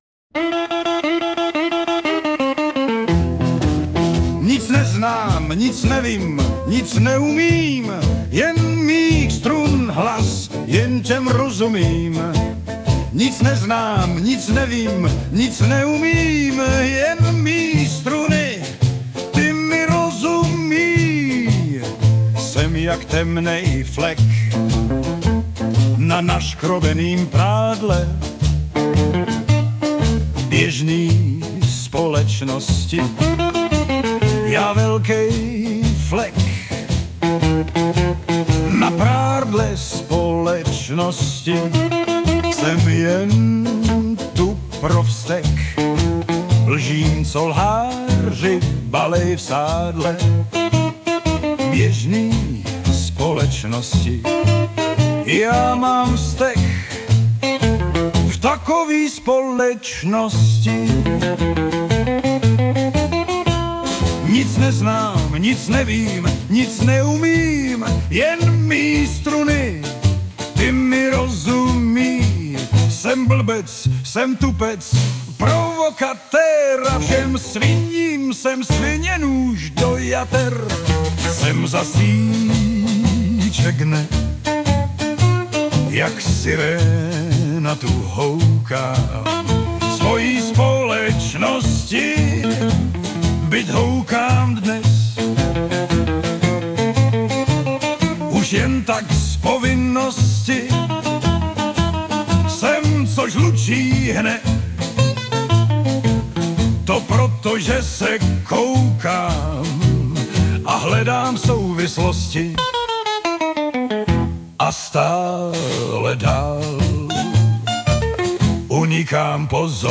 Anotace: Zas jedna dopsaná, poupravená starší věc - trochu jsem to významově posunul a trochu víc - opakováním a rytmem - převedl do něčeho, čemu by se dalo říkat "delta blues".
úplná pecka k rozhoupání boků